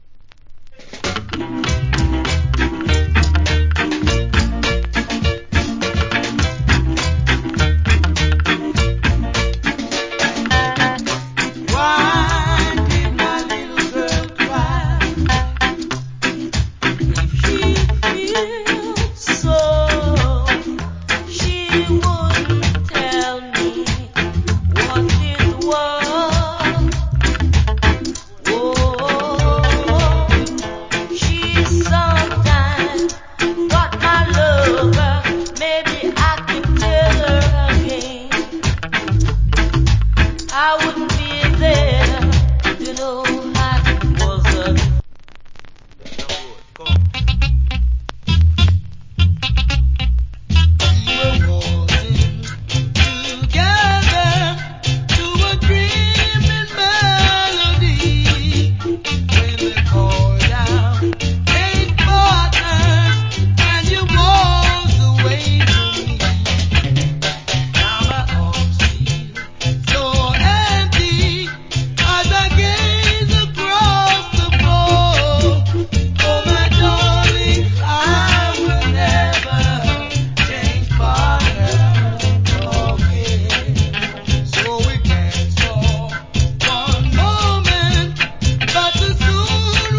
Cool Early Reggae Vocal.